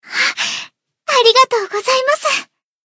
BA_V_Hinata_Battle_Recovery_1.ogg